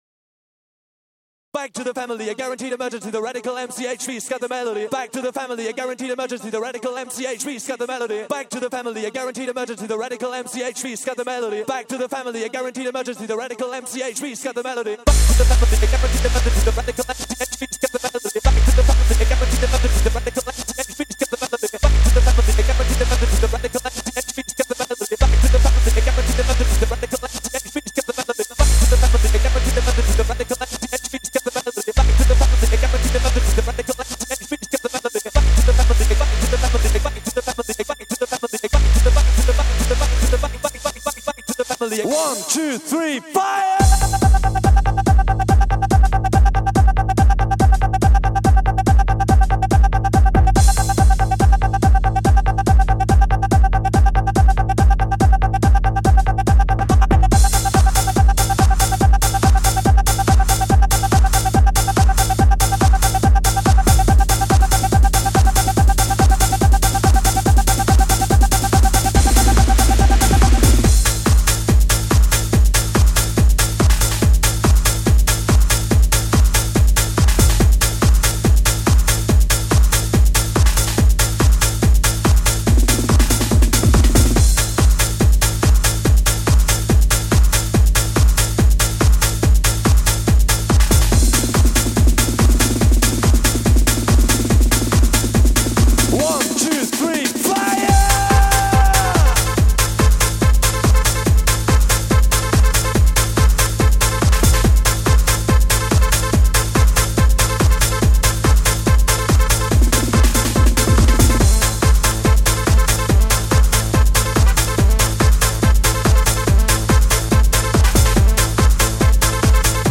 Жанр: Trance